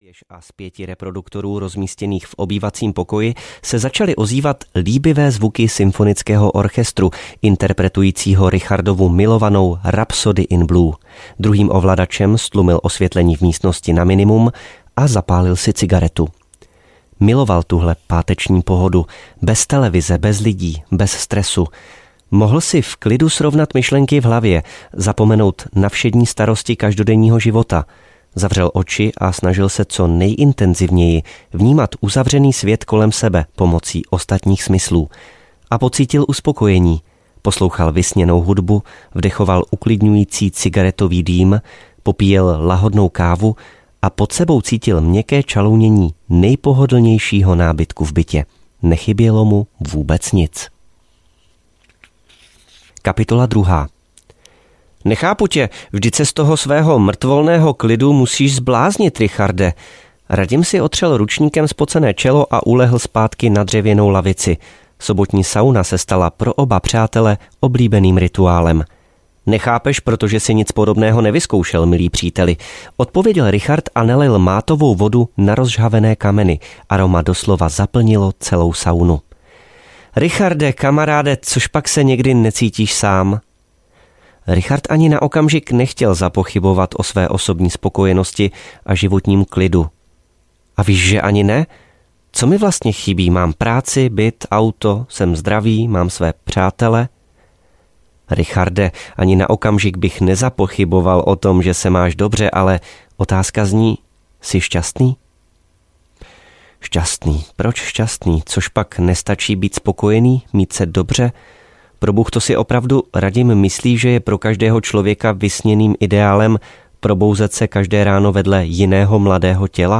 Podivný svět Richarda Krause audiokniha
Ukázka z knihy